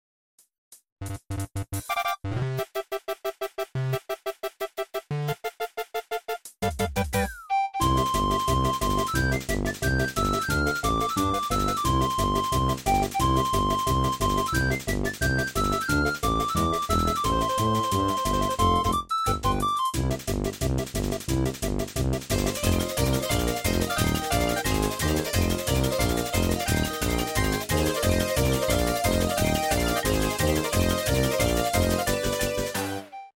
エレクトーン演奏
organ#stagia
ゲーム音楽アレンジ